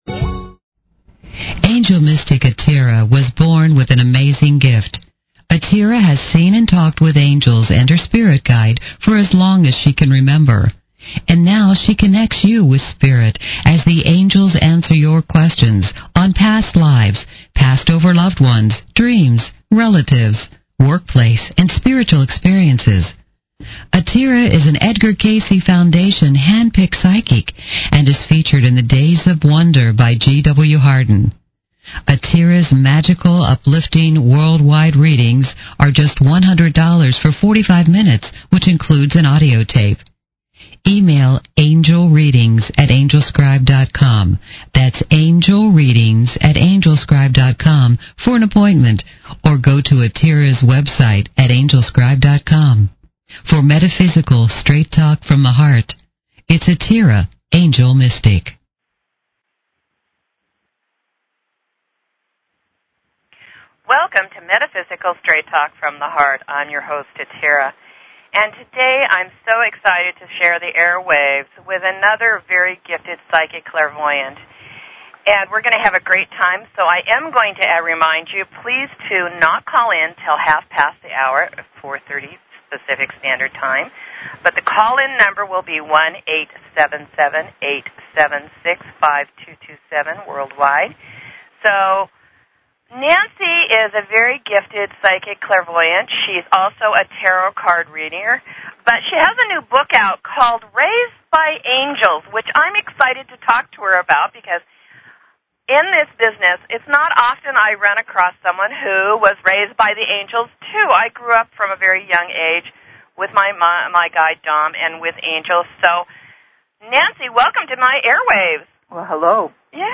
Talk Show Episode, Audio Podcast, Metaphysical_Straight_Talk and Courtesy of BBS Radio on , show guests , about , categorized as